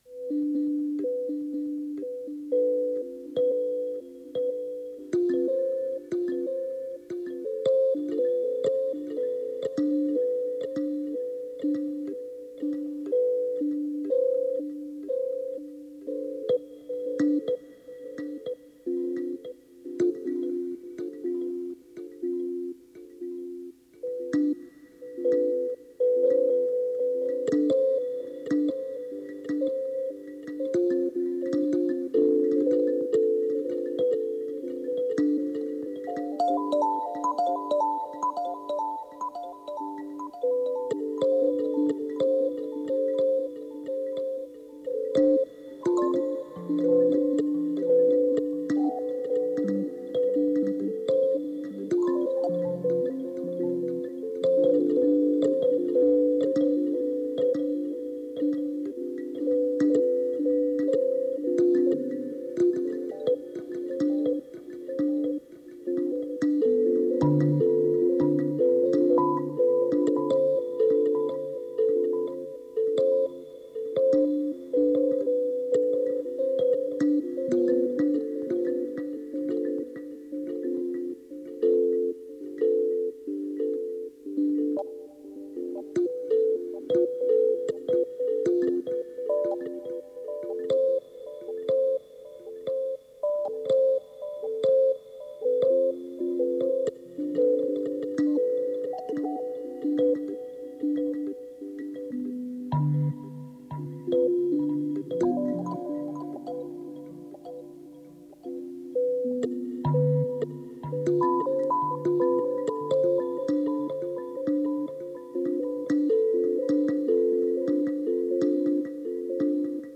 Threads of noise, tape loops, and samples.
It was one of those days - I didn’t go out, but managed to get a very minimal setup to noodle for a bit (kalimba/microphone/Norns/Cheat Codes 2).